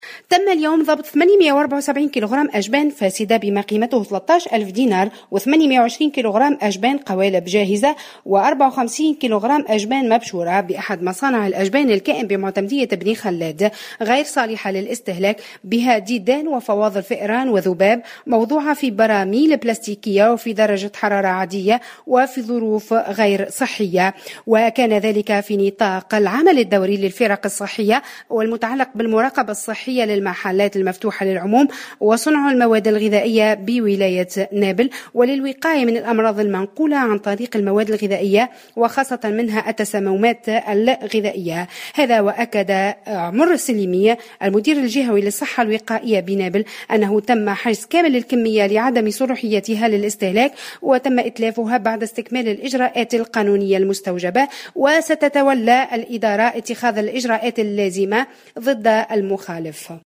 أكثر تفاصيل مع مراسلتنا في نابل تحميل المشاركة علي